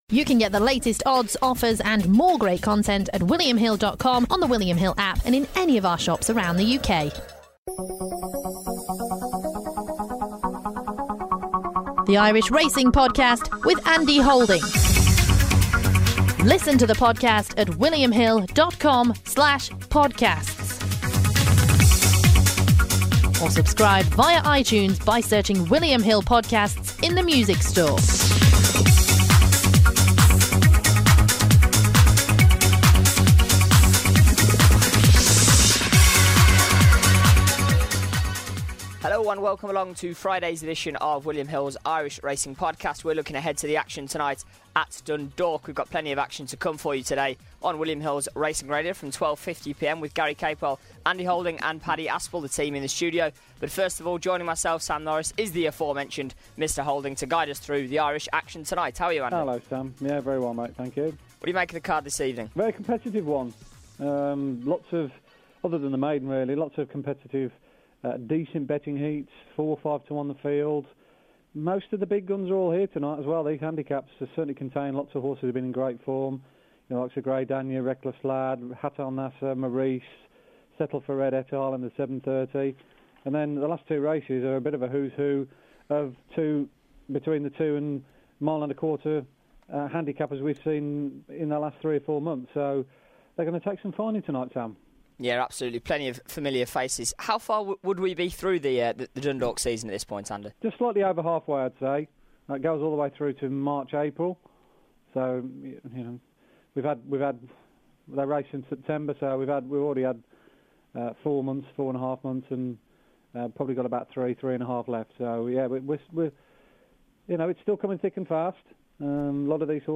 on the line to preview every race on the card before picking out his selections.